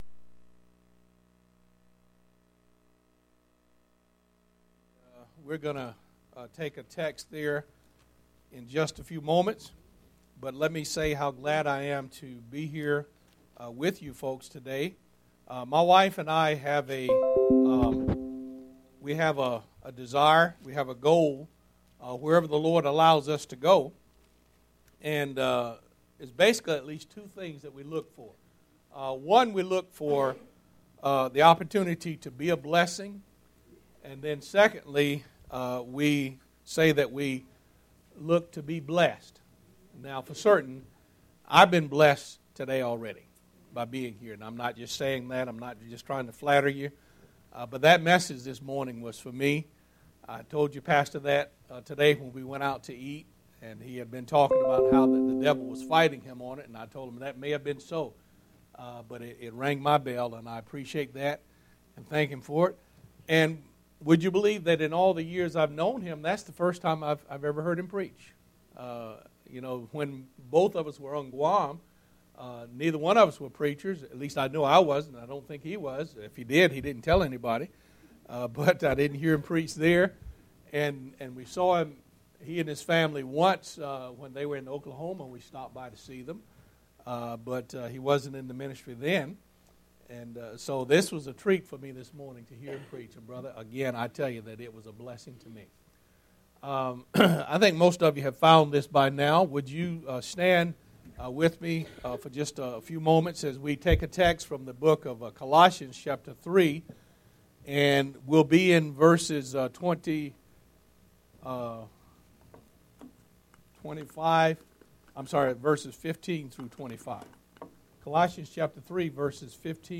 MISSIONARY ? PM Service 1/3/16